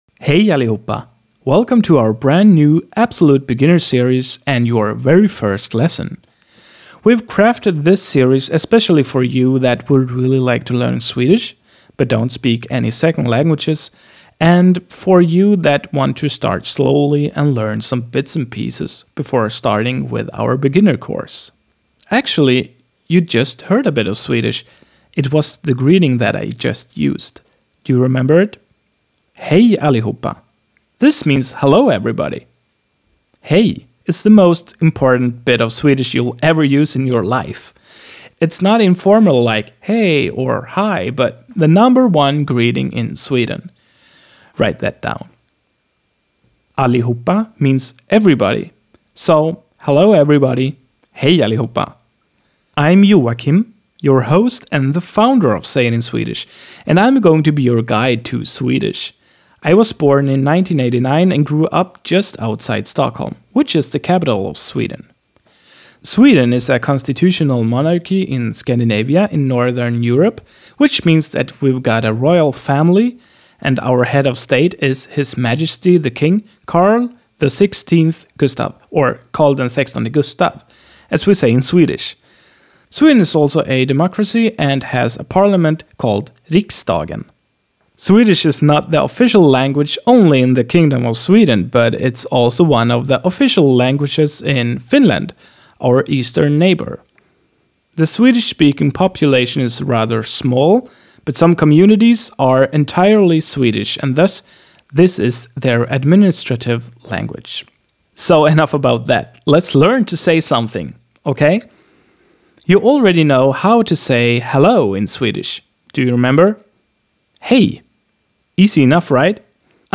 [siis-tip-box]In spoken Swedish, you'll mostly hear jag being realized as /ja/ and är as /e/ or /ä/. That is, both words lose their ending consonant.[/siis-tip-box]